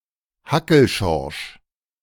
Georg Hackl (German pronunciation: [ˈhakl̩ ʃɔʁʃ]